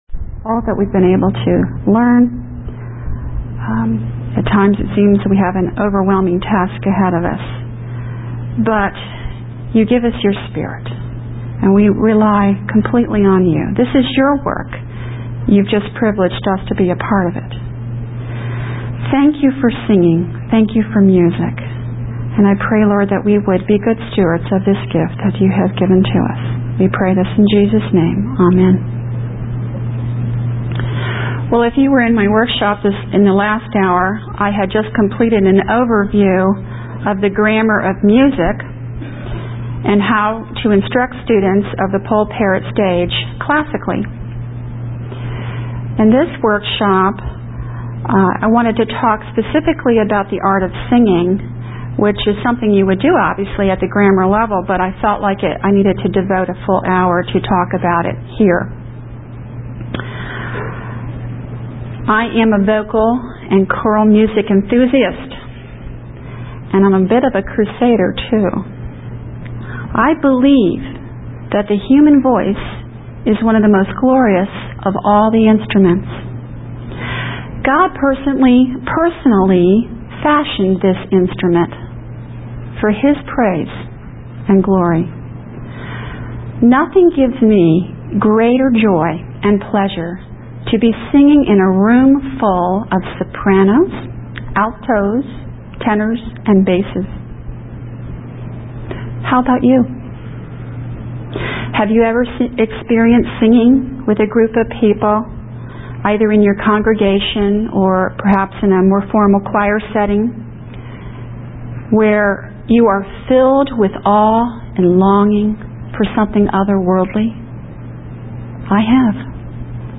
2003 Workshop Talk | 1:05:39 | All Grade Levels, Art & Music